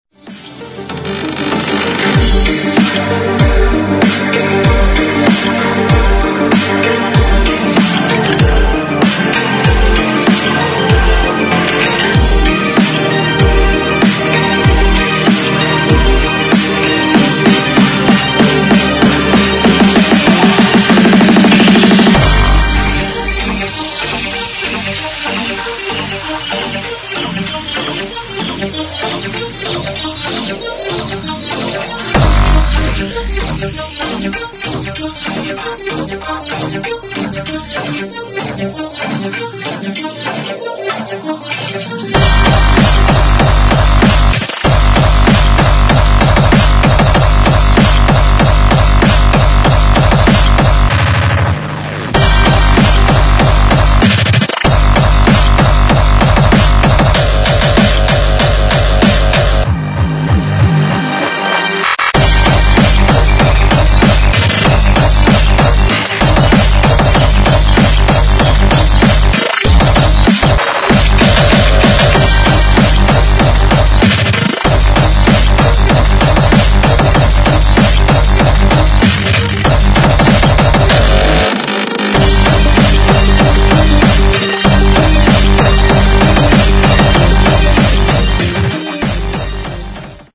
[ HARDCORE / DRUM'N'BASS ]